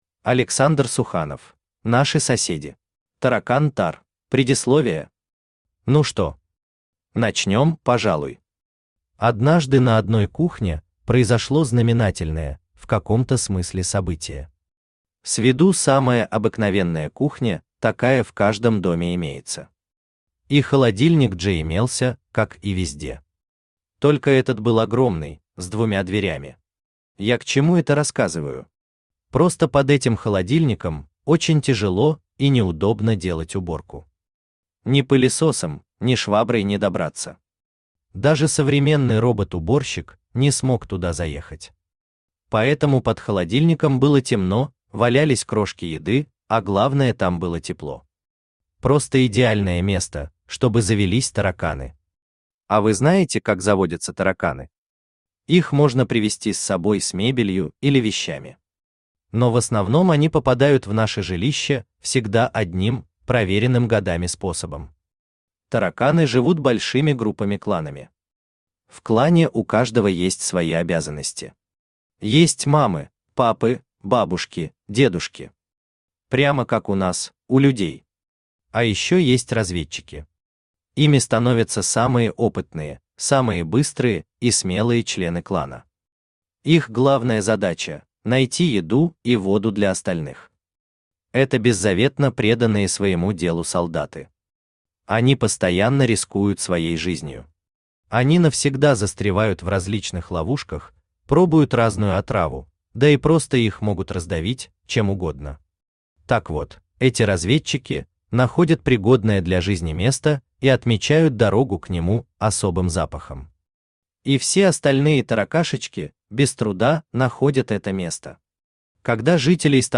Aудиокнига Наши соседи Автор Александр Геннадьевич Суханов Читает аудиокнигу Авточтец ЛитРес.